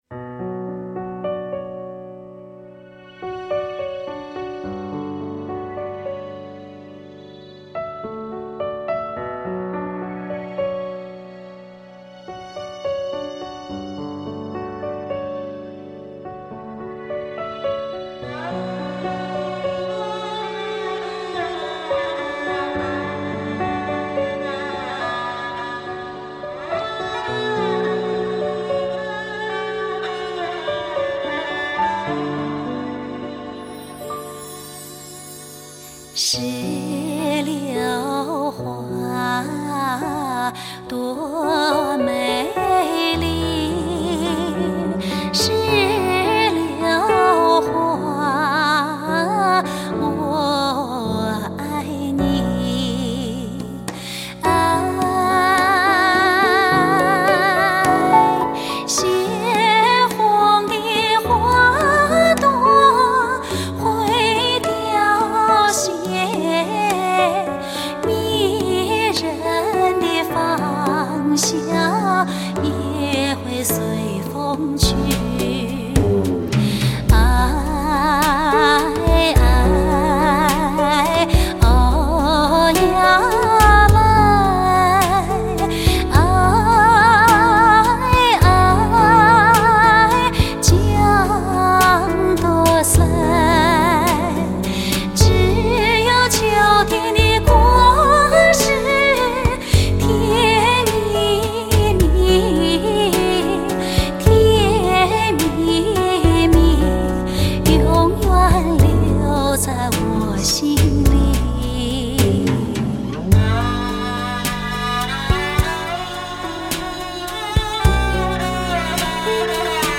边疆情歌